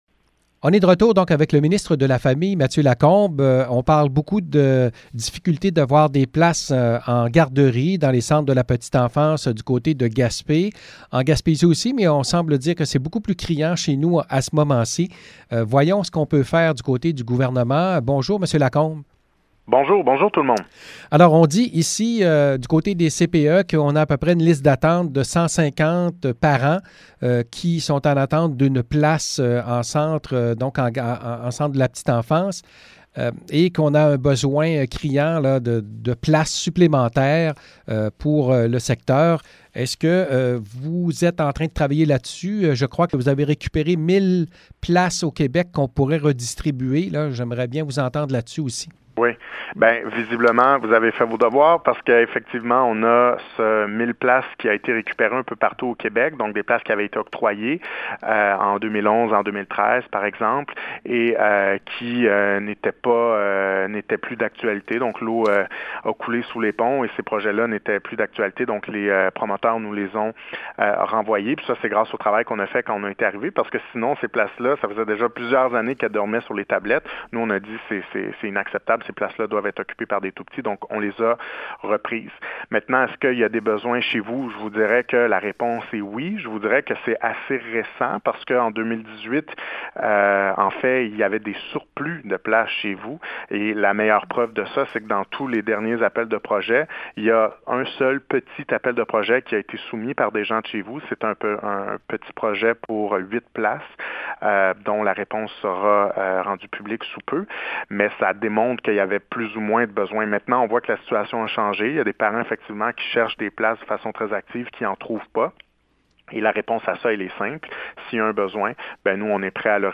Entrevue avec Mathieu Lacombe: